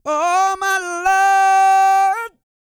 E-CROON 212.wav